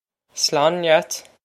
Pronunciation for how to say
Slawn lyat!
This is an approximate phonetic pronunciation of the phrase.